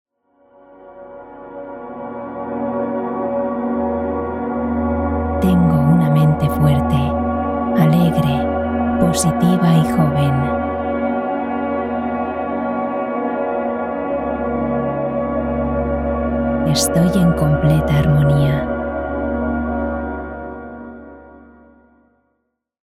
Una hora de meditaciones en audios con frases y pensamientos positivos basados en técnicas de Programación Neurolingüística (PNL) acompañados de música y frecuencias que favorecen las ondas Alfa y Theta cerebrales para la relajación y la atención profunda.